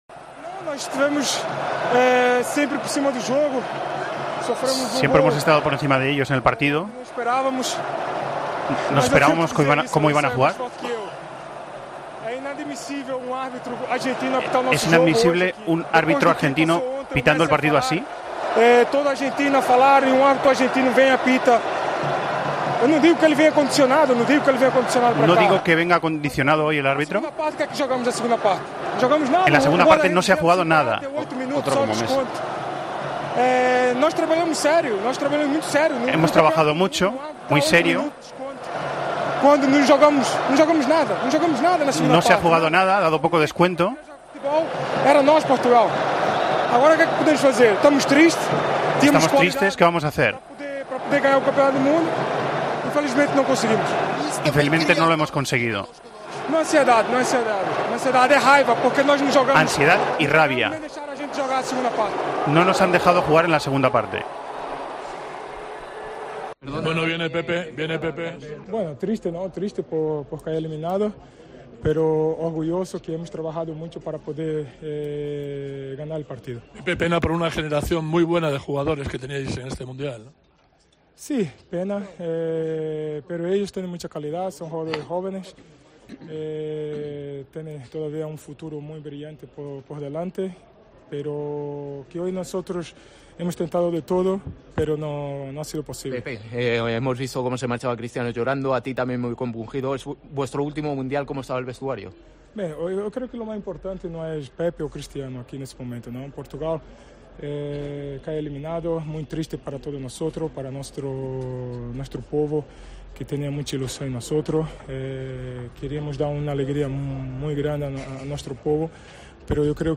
El central de Portugal se mostró furioso tras la eliminación de su equipo y lamentó el planteamiento de Marruecos: "En la segunda parte no se ha jugado nada".
Tras que Portugal quedase eliminada del Mundial por la derrota en cuartos de final ante Marruecos (1-0), Pepe fue uno de los encargados de hablar en la zona mixta y en la 'flash interview' del Al-Thumama Stadium.